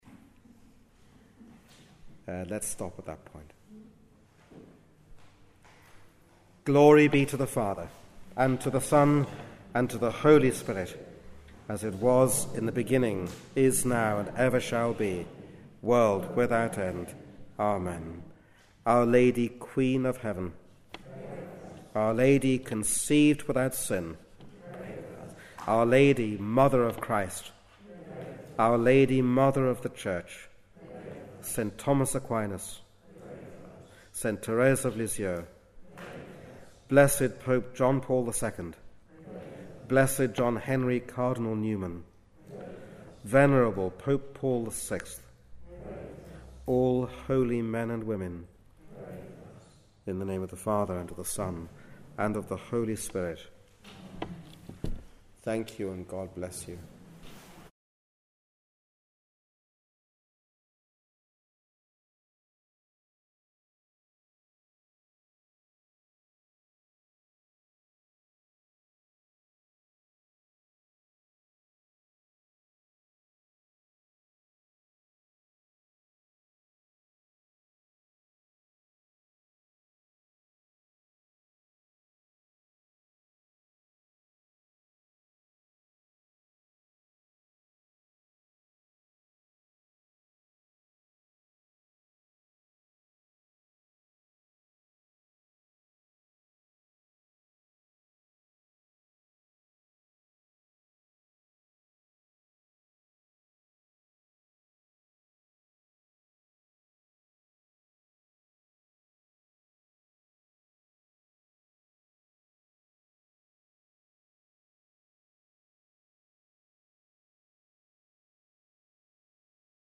A lecture given during a conference at the School of the Annunciation in October 2015.